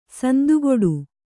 ♪ sandugoḍu